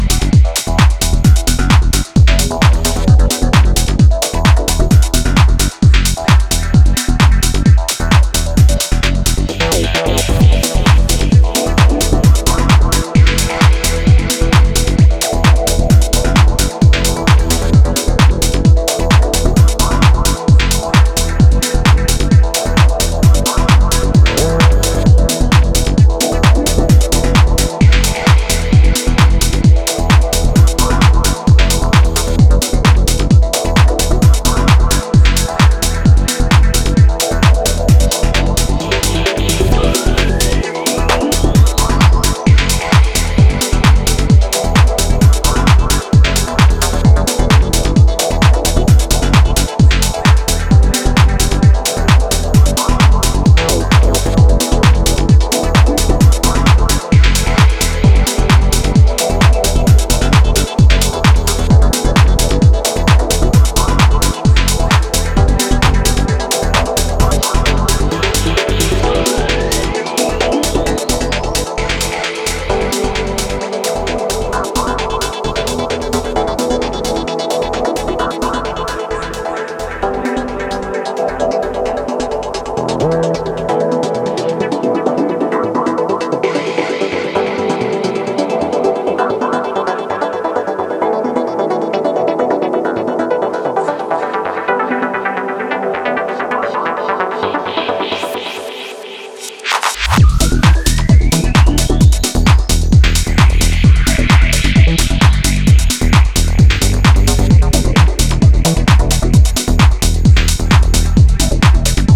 pumpy uplifting house
solid rolling remix